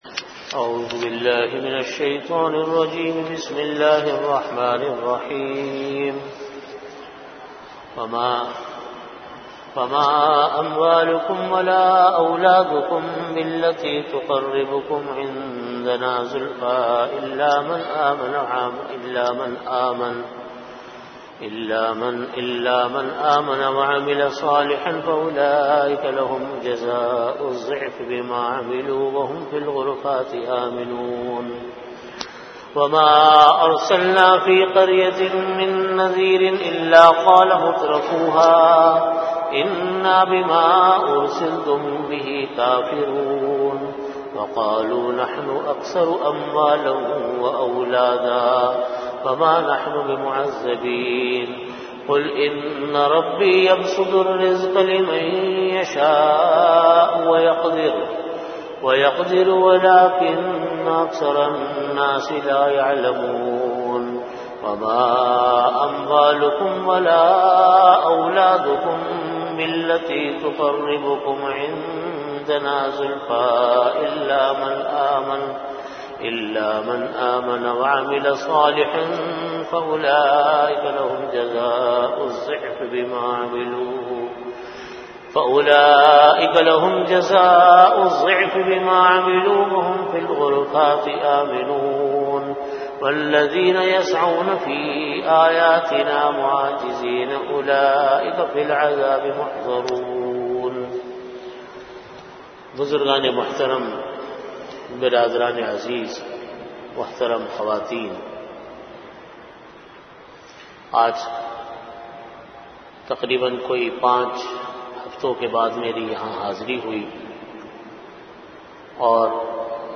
Category: Tafseer
Venue: Jamia Masjid Bait-ul-Mukkaram, Karachi